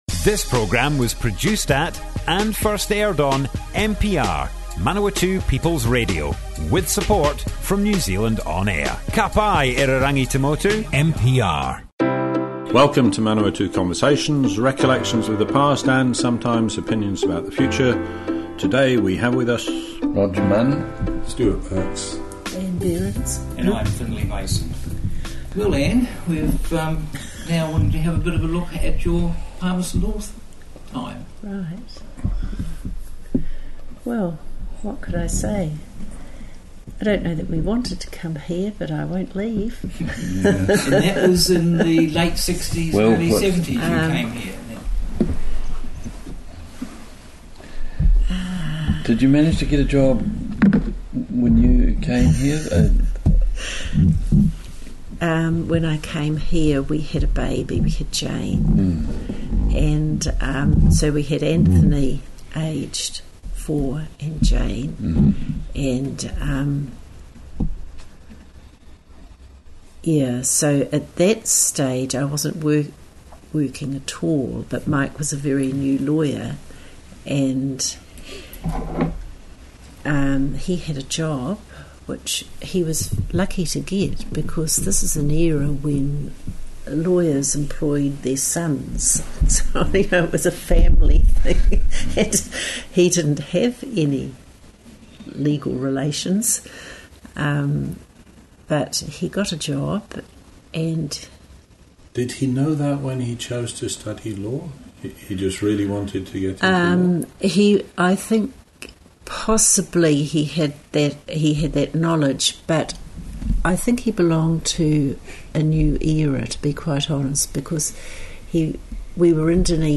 Manawatu Conversations More Info → Description Broadcast on Manawatu People's Radio 4 September 2018.
oral history